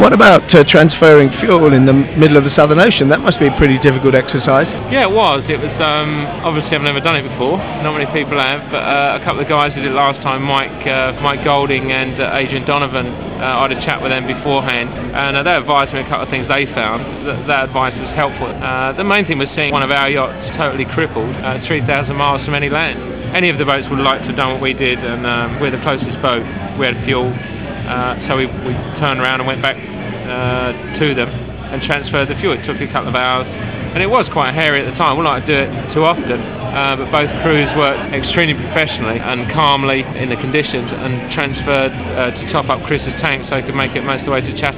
The following clips were recorded during the race.
Mike Golding, Skipper, Group 4